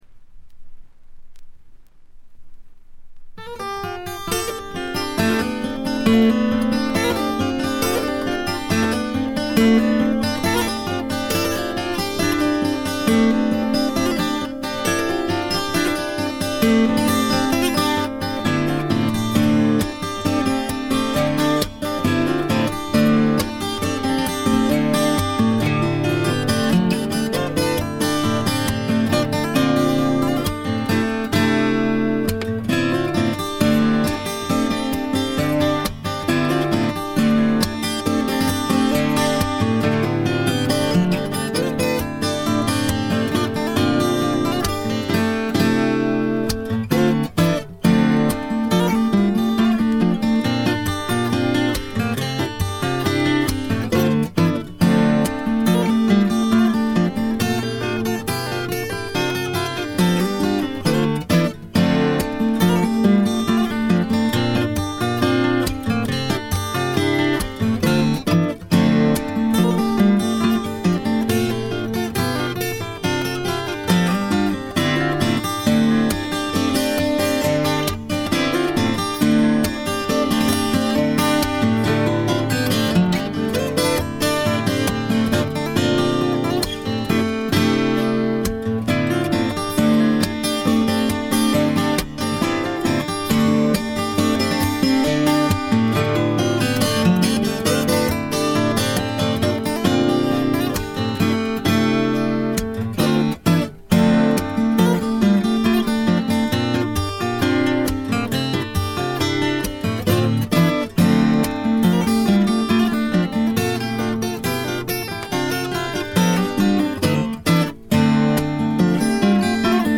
ごくわずかなノイズ感のみ。
試聴曲は現品からの取り込み音源です。
Recorded at studio Kamboui, Chatellerault, France.